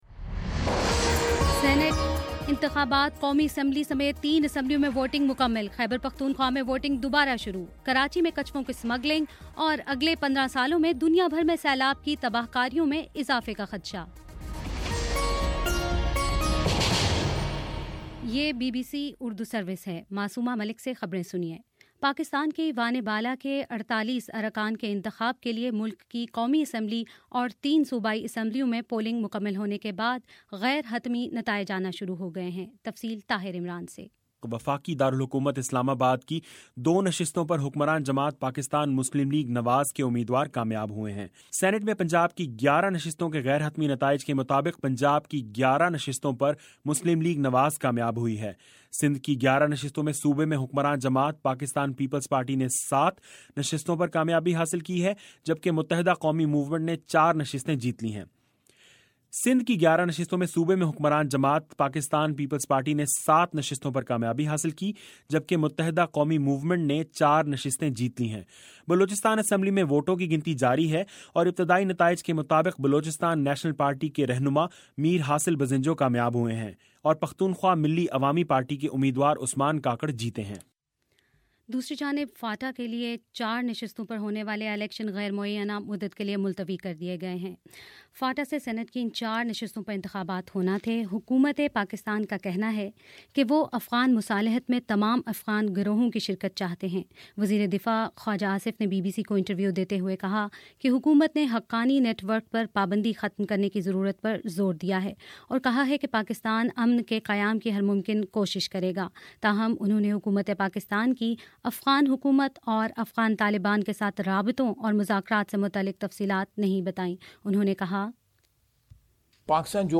مارچ 05: شام چھ بجے کا نیوز بُلیٹن